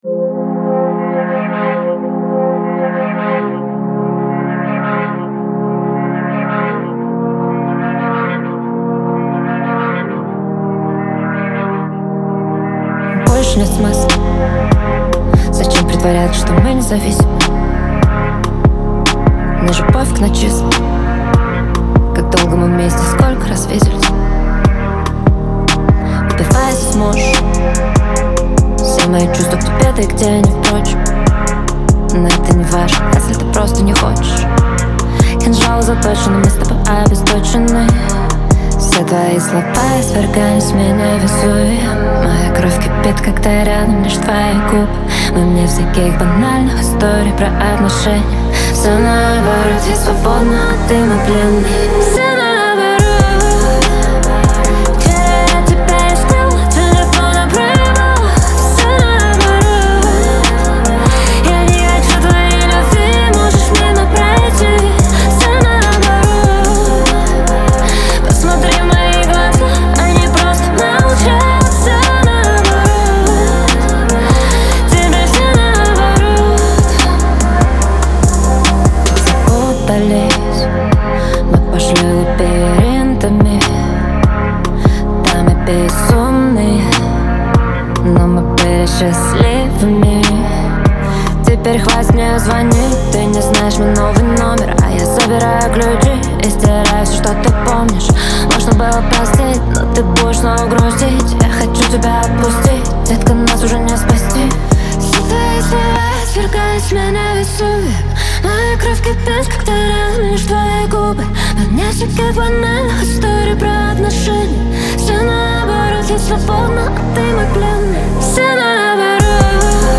мощный вокал и запоминающиеся мелодии